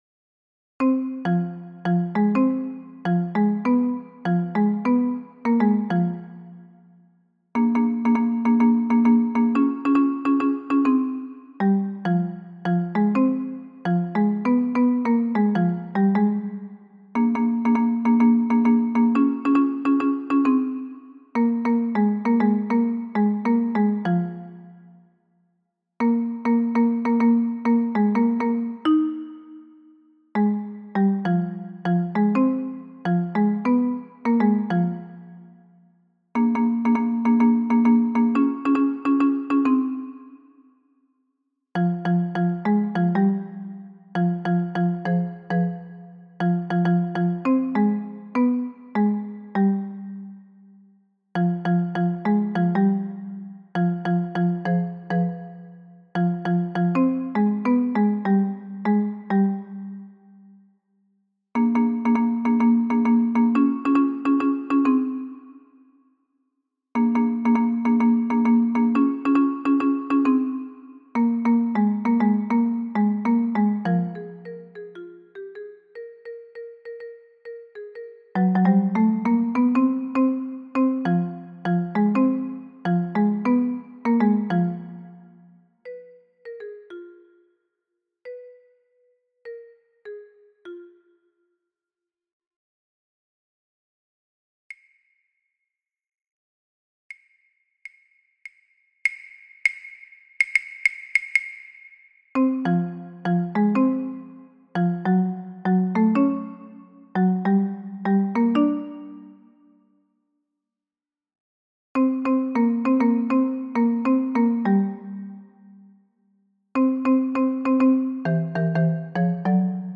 Tenor et autres voix en arrière-plan